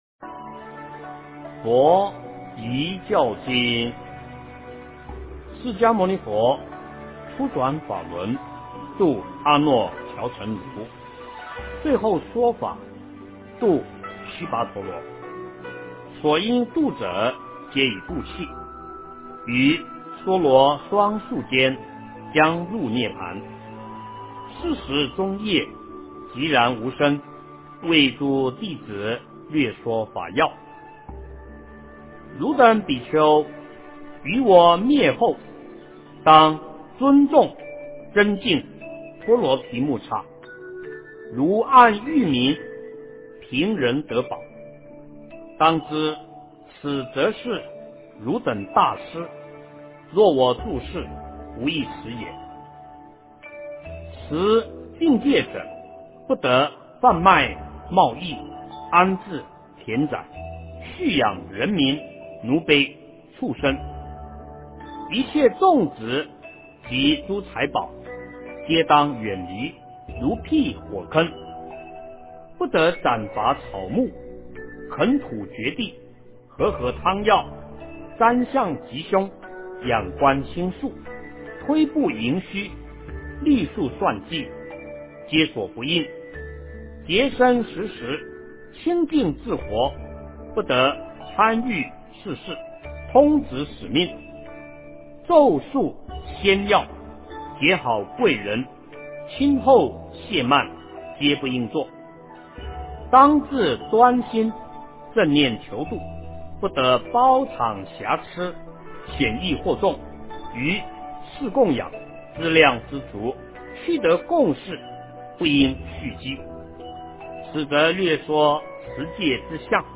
佛教遗经（念诵）
诵经